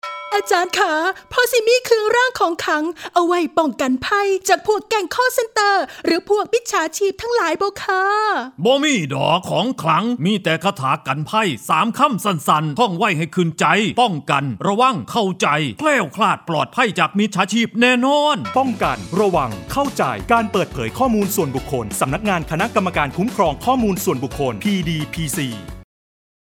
ภาคอีสาน
ภาคอีสาน.mp3